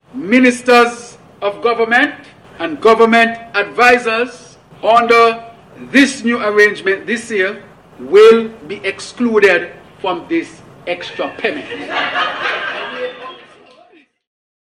This he said, while presenting the 2026 Budget Address in the National Assembly on Tuesday Dec. 16th, which was held under the theme “Investing in People and Progress.”